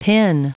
Prononciation du mot pin en anglais (fichier audio)
pin.wav